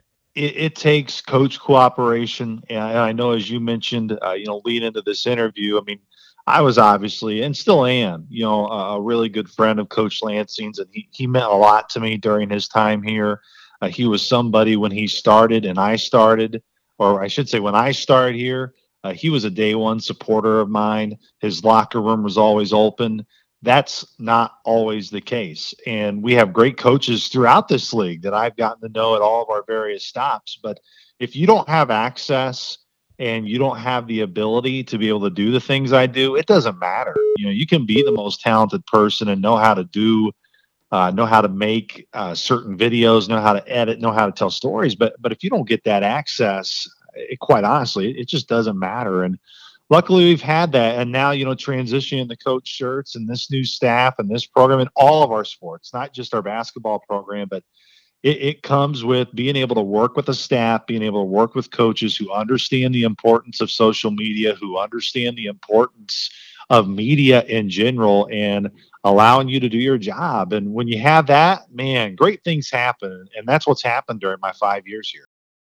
During our interview we discussed both the roster and challenges that lie ahead.